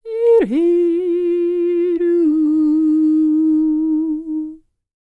K CELTIC 32.wav